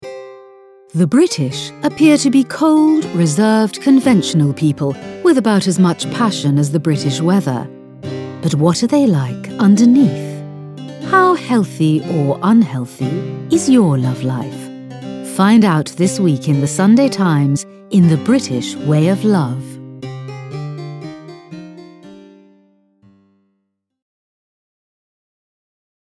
English british female voice over artist. sophisticated, warm, friendly , business voice
Sprechprobe: Sonstiges (Muttersprache):
I have a clear friendly warm british voice.